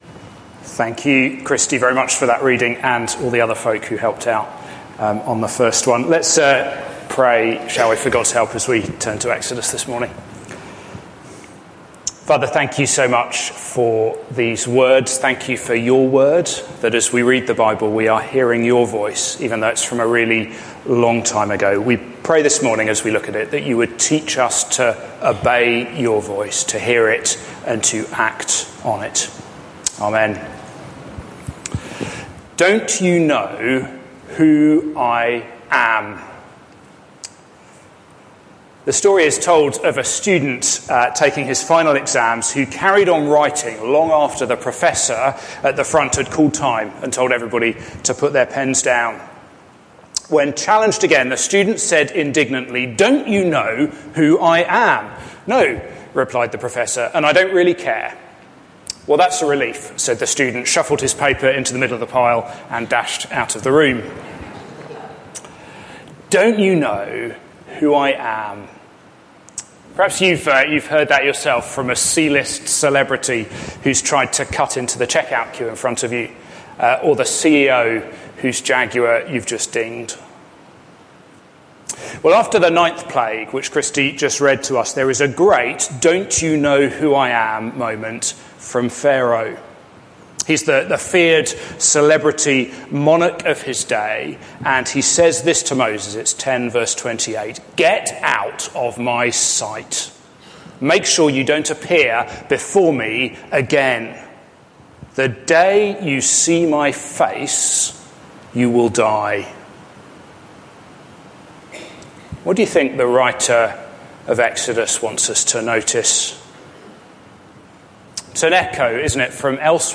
This sermon is part of a series: